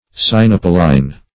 Search Result for " sinapoline" : The Collaborative International Dictionary of English v.0.48: Sinapoline \Si*nap"o*line\, n. [Sinapis + L. oleum oil.]